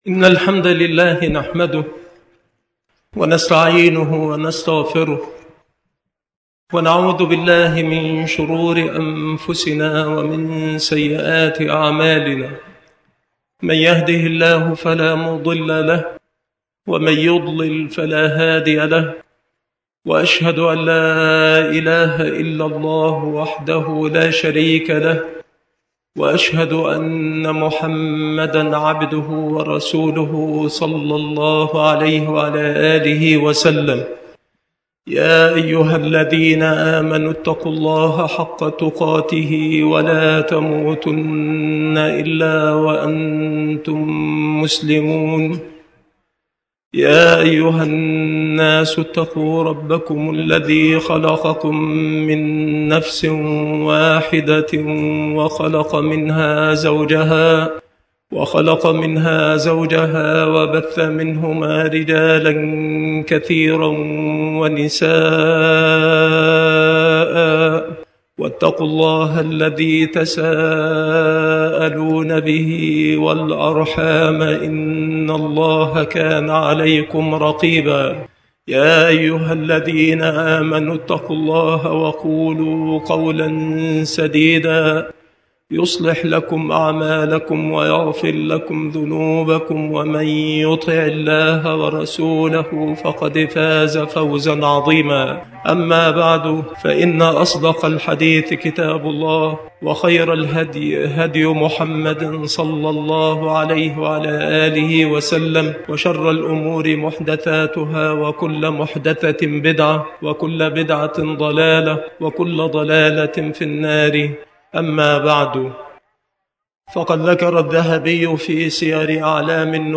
خطب العيدين
بساحة سوق القرية بسبك الأحد - أشمون - محافظة المنوفية - مصر